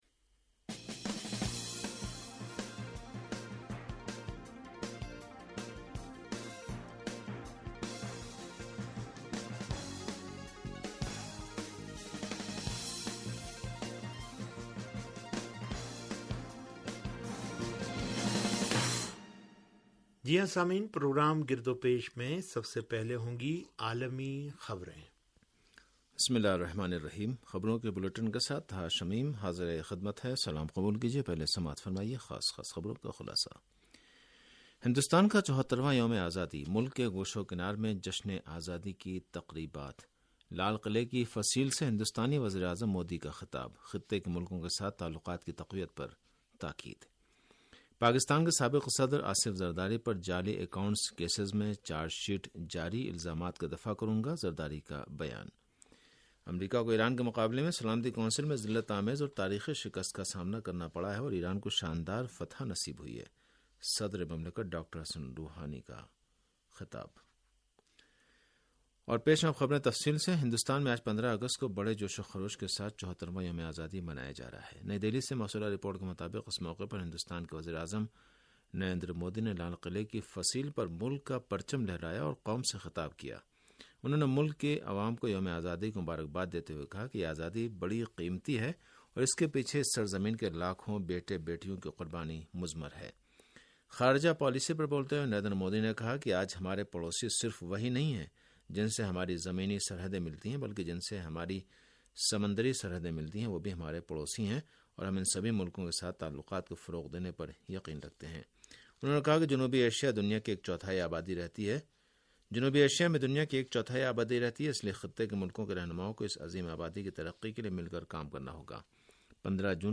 ریڈیو تہران کا سیاسی پروگرام - گرد و پیش